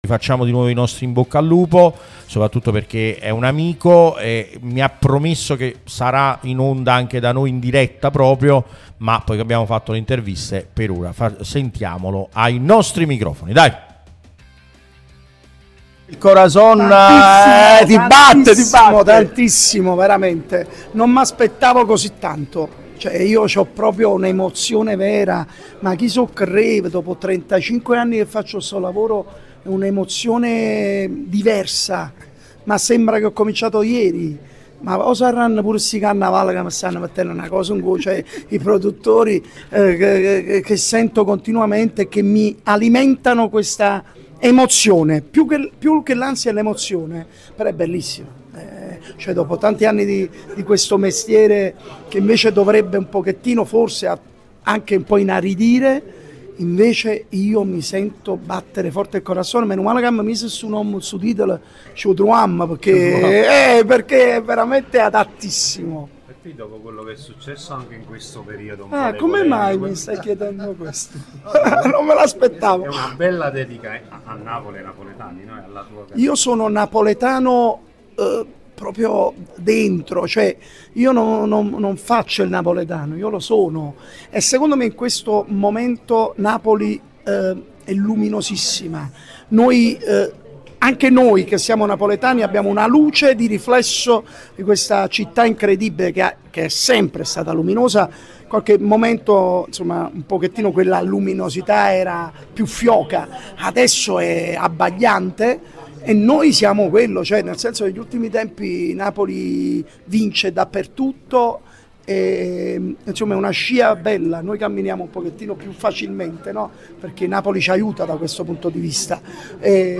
A margine della presentazione Iodice è intervenuto su Radio Tutto Napoli, l'unica radio tutta azzurra e sempre live, che puoi seguire sulle app gratuite (per Iphone o per Android, Android Tv ed LG), in auto col DAB o qui sul sito anche in video: "Mi batte tantissimo il corazón! Veramente non mi aspettavo così tanto, cioè io ho proprio un’emozione vera, ma chi scrive dopo 35 anni che faccio questo lavoro è un’emozione diversa.